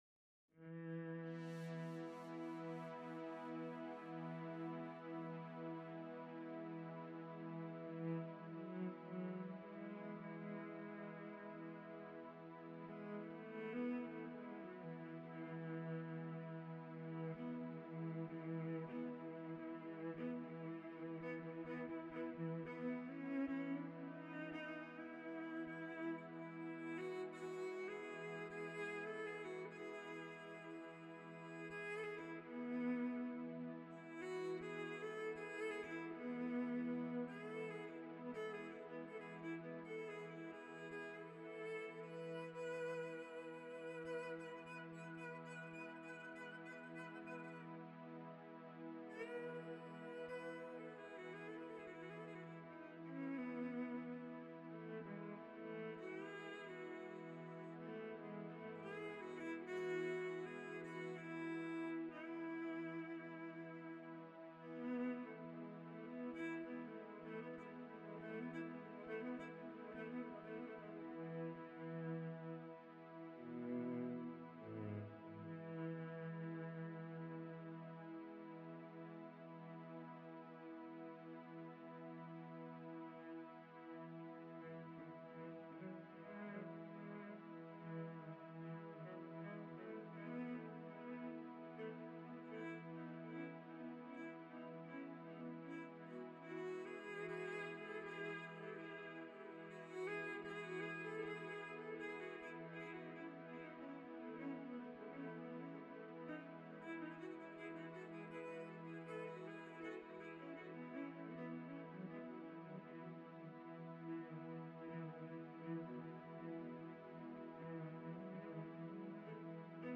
OK - so….I play one single note on my violin It gets converted to a MIDI note through A2M Then the Mozaic Script below…..takes that one MIDI note and
I’ve attached an MP3 with my string pad….(uh, stringing you along), and then me noodling on top